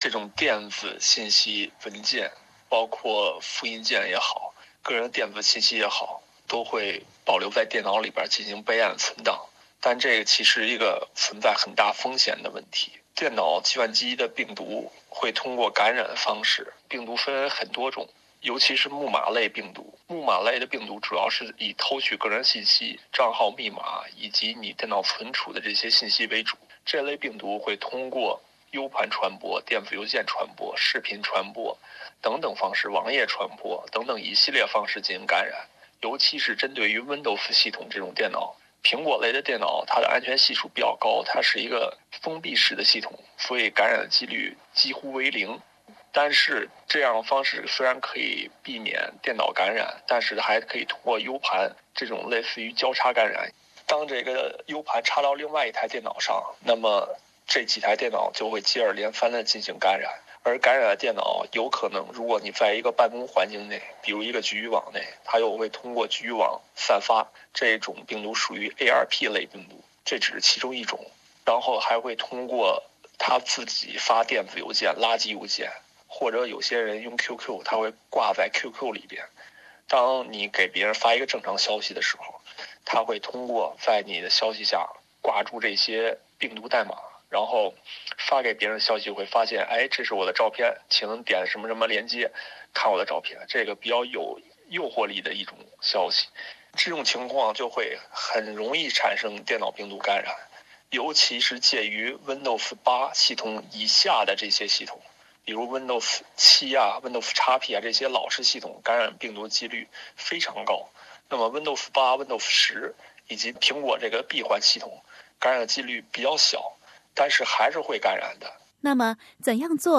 让我们来听听他的建议。